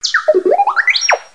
Звуки дроида R2D2 из звёздных войн в mp3 формате